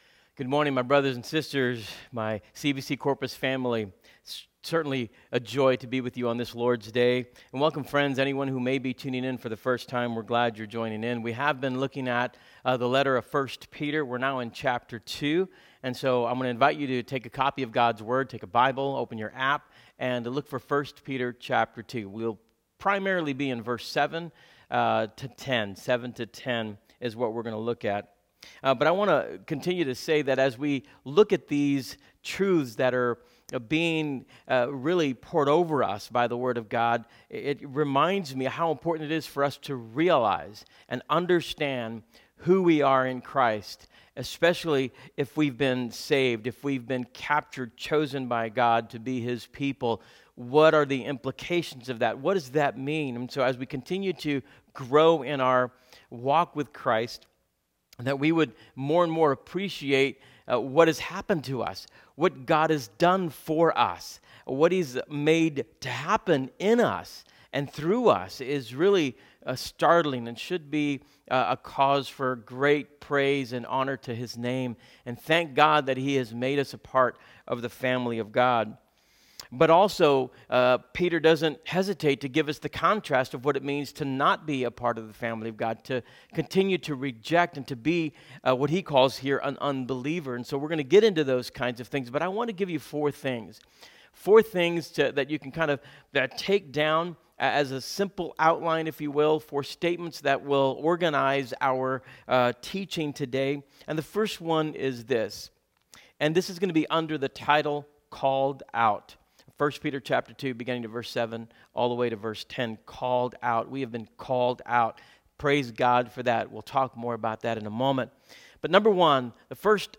Sermons | Christ Redeemer Church